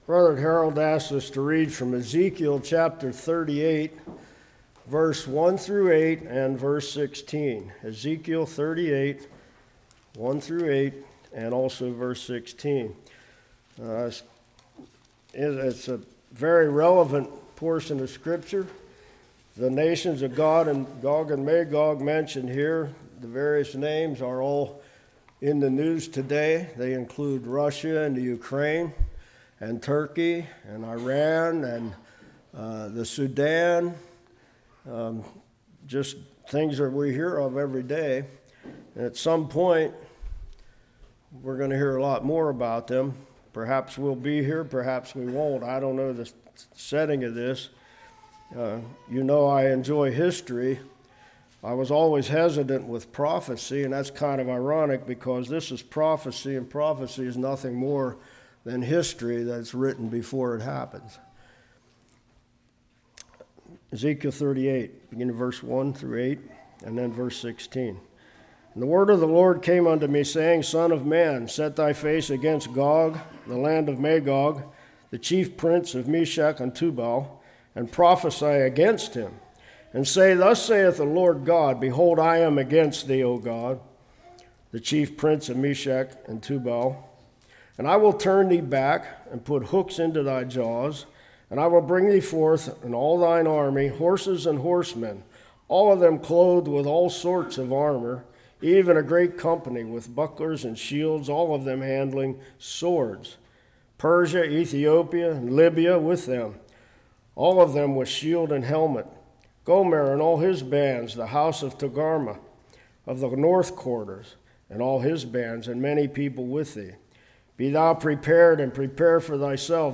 Ezekiel 38:1-8,16 Service Type: Morning Wars